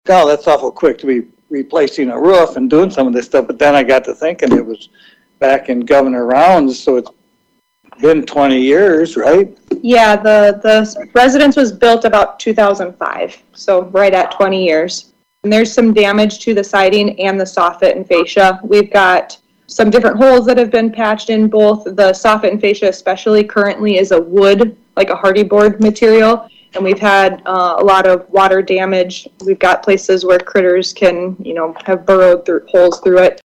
Committee member and Pierre Mayor Steve Harding said he can’t believe it’s already time to do some improvements.